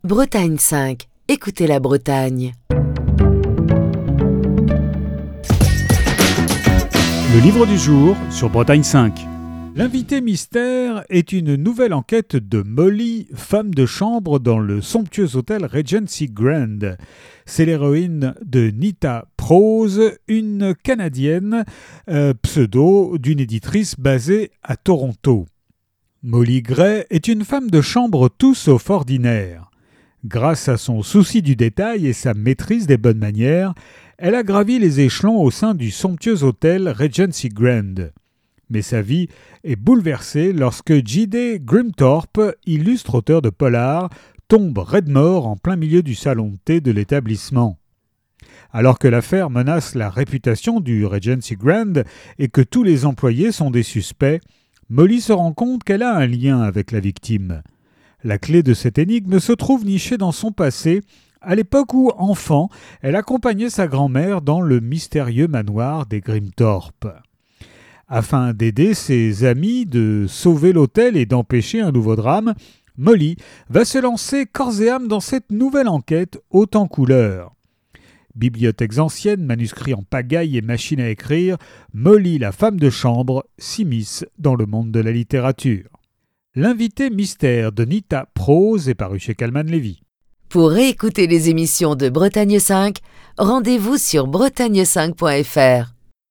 Chronique du 5 avril 2024.